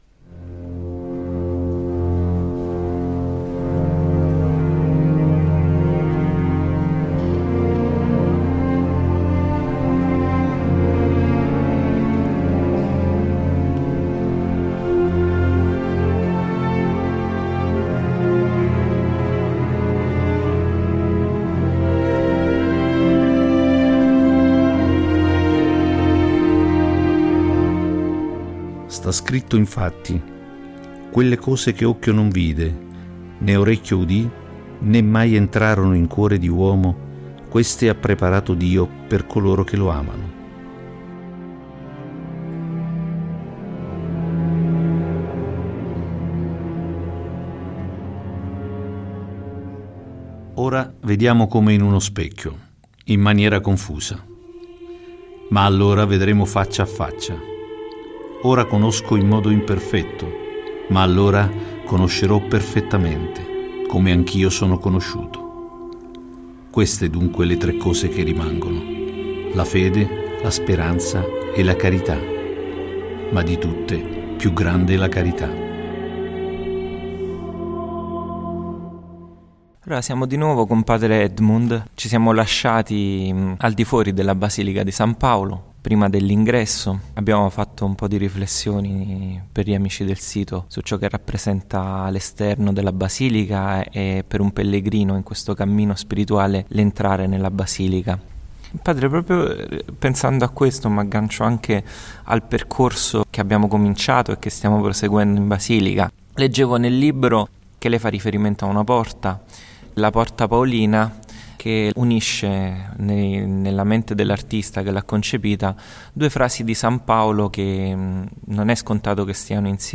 CIO-CHE-OCCHIO-NON-VIDE-PUNATAT-4-CON-EFFETTI.mp3